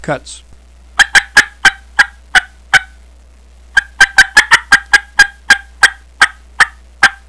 Listen to 7 seconds of cutts
primosraspydoublehookcutts7.wav